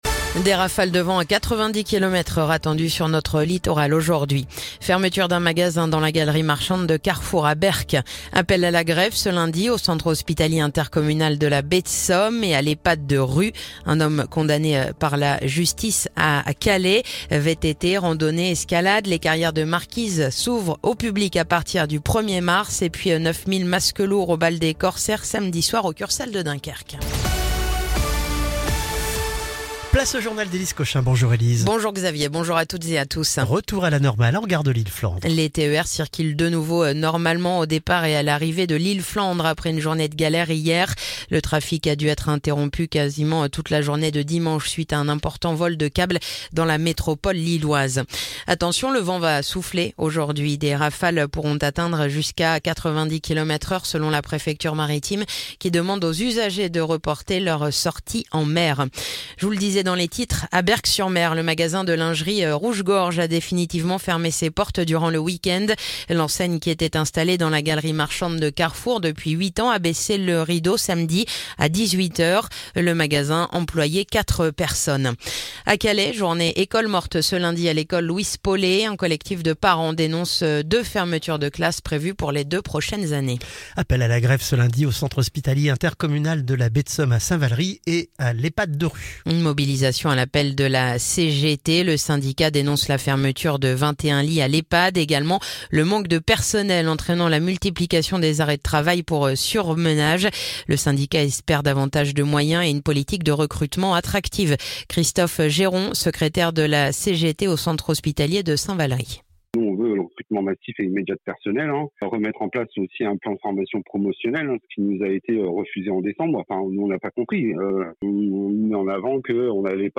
Le journal du lundi 24 février